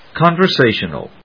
音節con・ver・sa・tion・al 発音記号・読み方
/kὰnvɚséɪʃ(ə)nəl(米国英語), k`ɔnvəséɪʃ(ə)nəl(英国英語)/